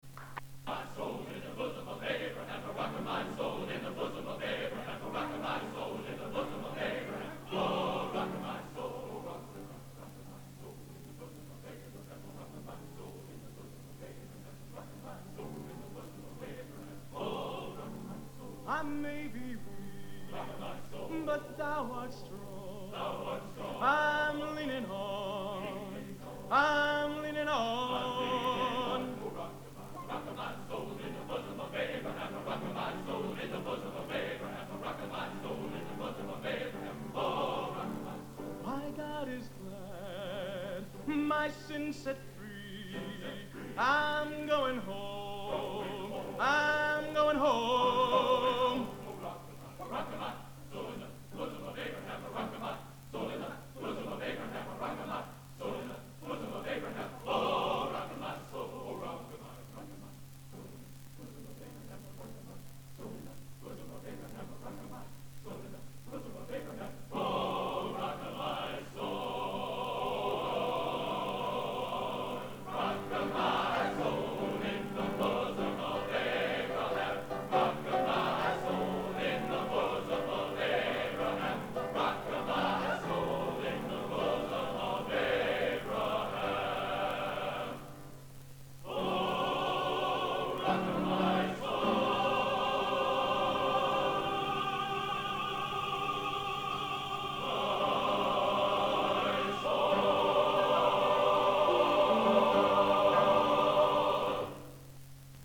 Genre: Spiritual | Type: